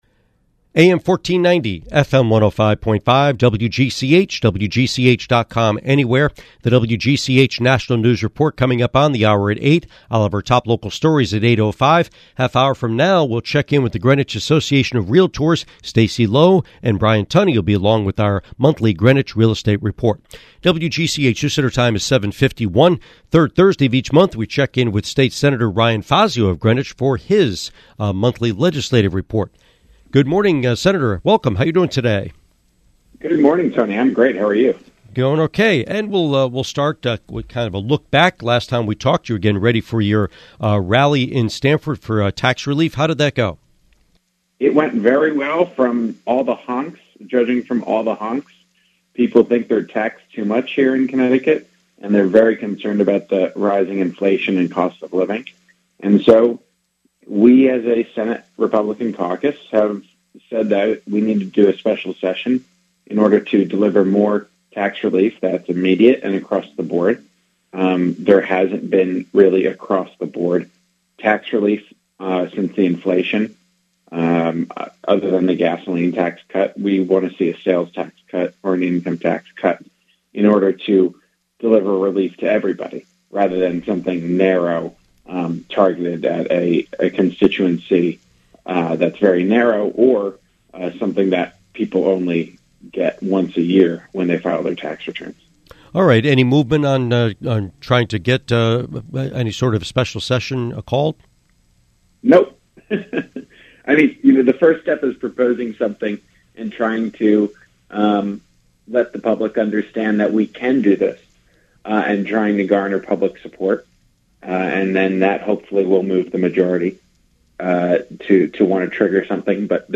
Interview with State Senator Fazio